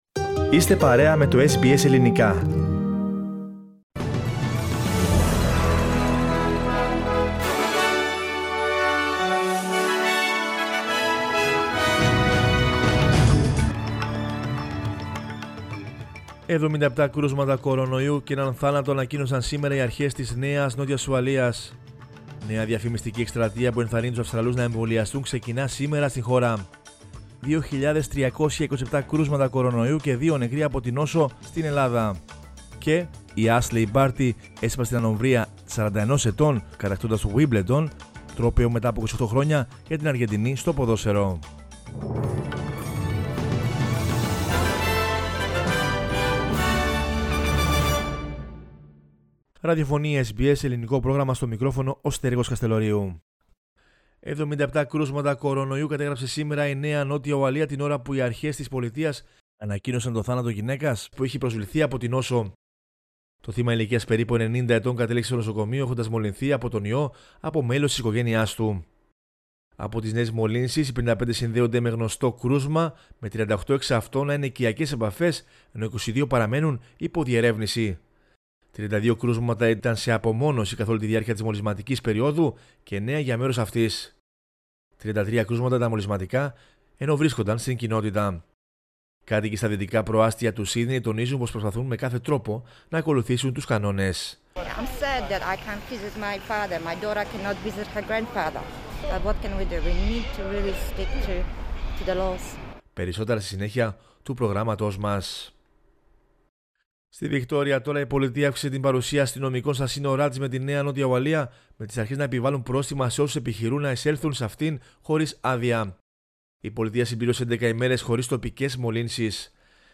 News in Greek from Australia, Greece, Cyprus and the world is the news bulletin of Sunday 11 July 2021.